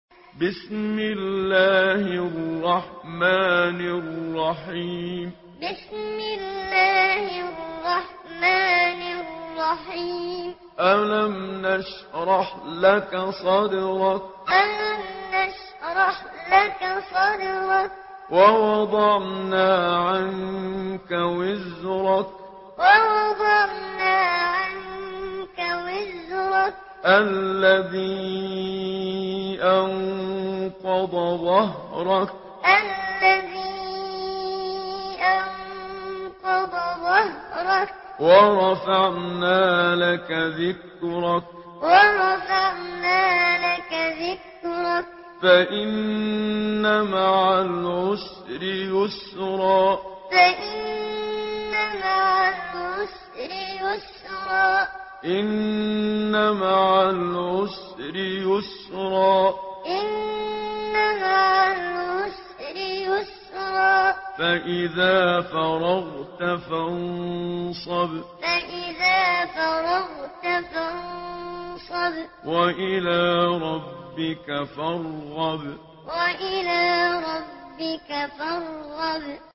Surah الشرح MP3 in the Voice of محمد صديق المنشاوي معلم in حفص Narration